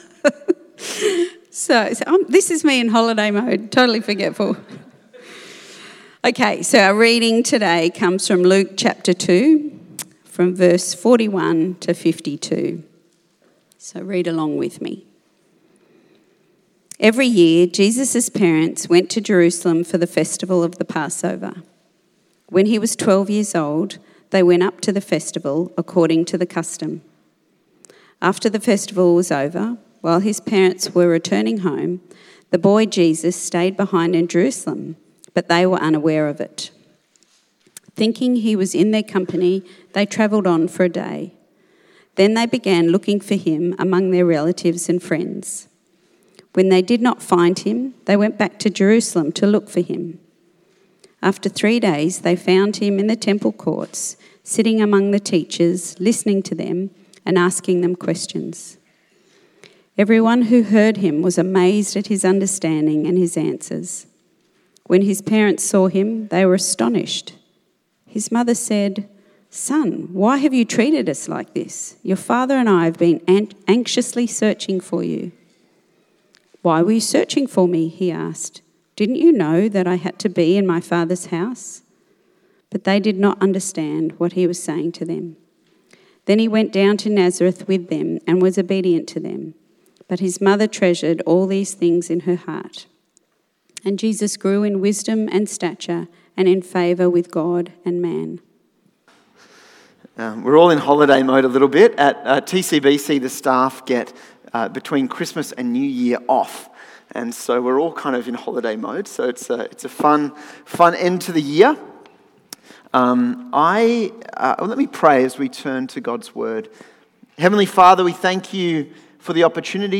Service Type: 4PM